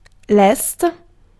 Ääntäminen
IPA: /ɛst/